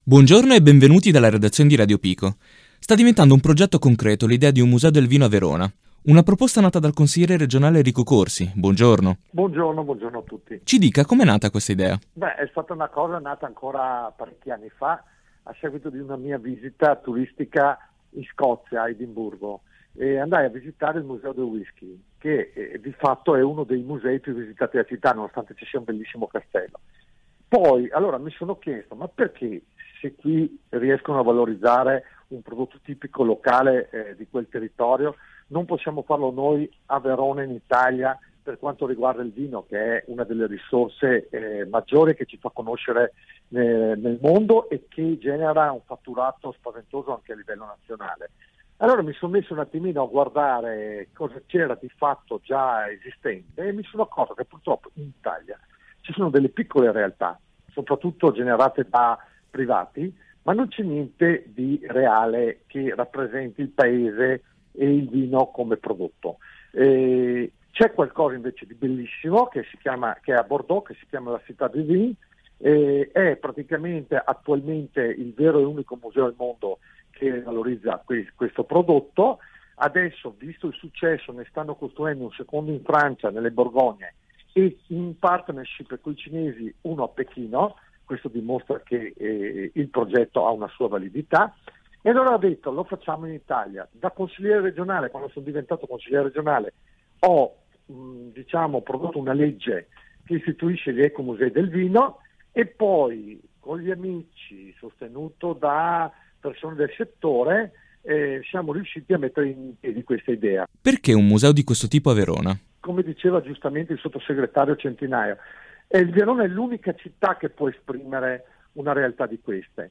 Il museo del vino muove i primi passi verso una concreta realizzazione a Verona. Ne abbiamo parlato con il consigliere regionale Enrico Corsi ideatore del progetto. Di seguito l’intervista.